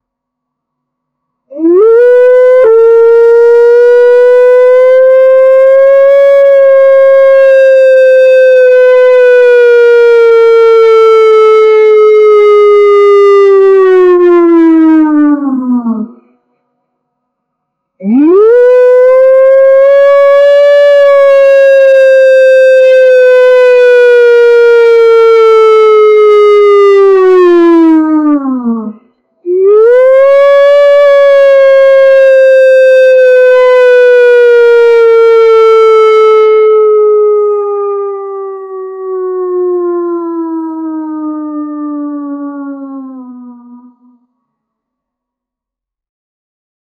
Siren was wooooooooooooooooooo!
siren-was-woooooooooooooo-cxeual55.wav